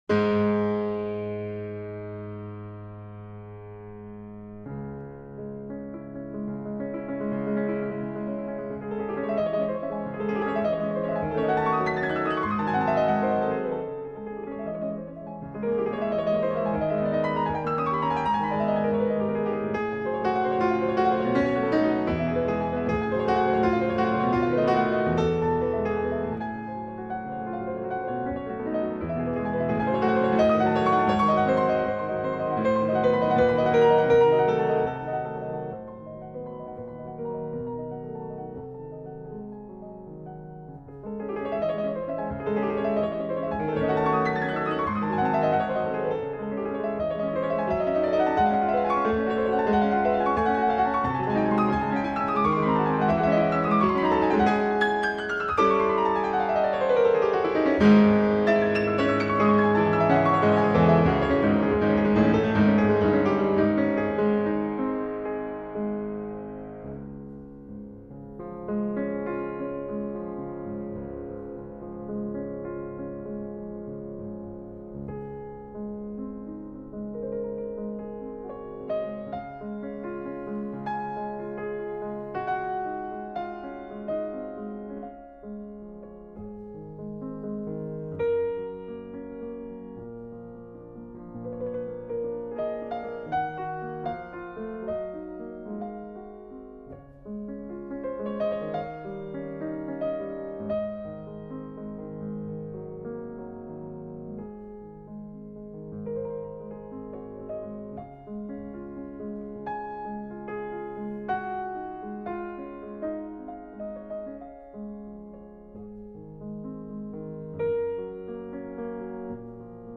Incontro con Lang Lang